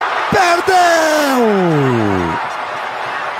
Decepcionado com gol perdido, Galvão Bueno narra "perdeeeu"!